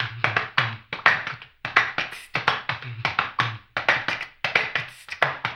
HAMBONE 05-L.wav